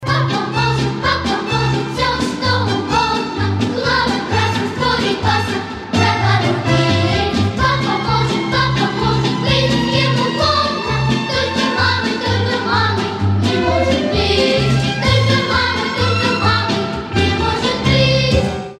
Детские рингтоны
Хор , Детский голос